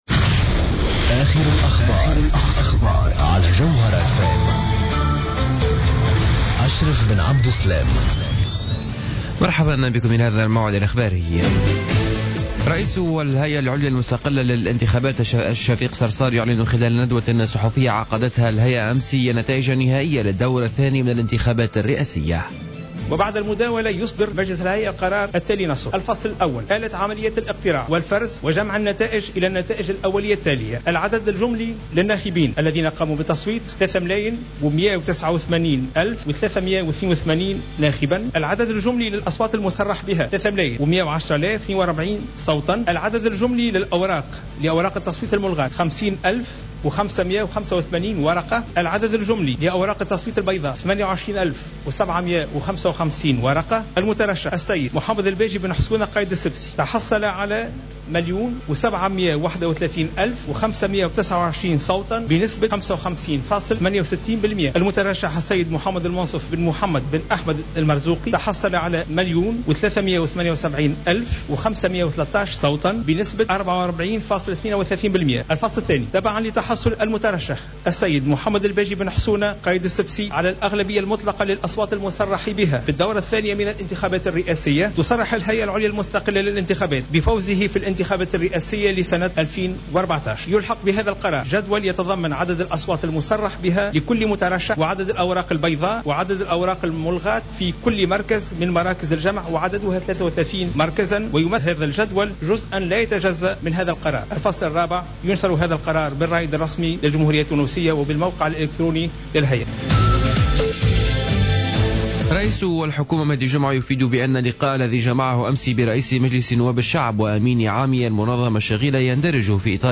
نشرة اخبار منتصف الليل ليوم الثلاثاء 30 ديسمبر 2014